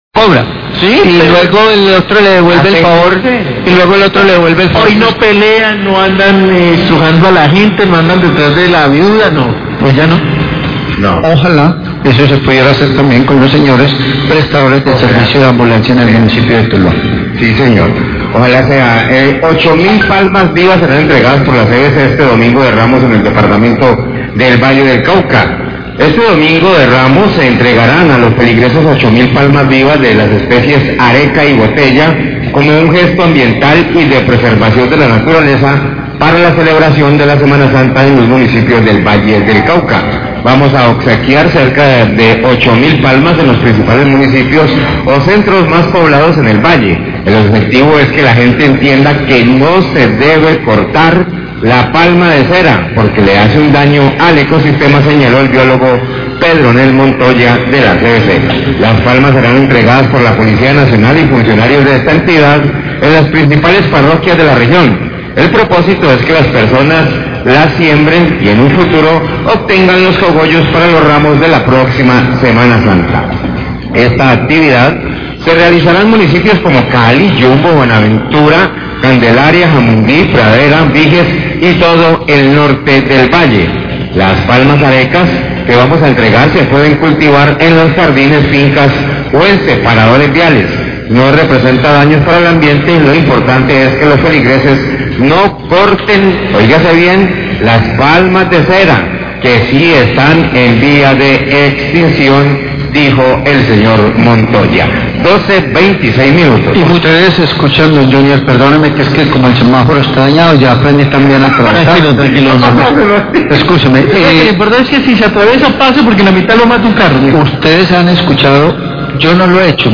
Radio
Panelista del informativo cuestiona este gesto de la CVC porque dice que siempre se hace este tipo de actividades faltando pocos días para semana Santa y no se hace este tipo de campañas de sensibilización durante todo el año.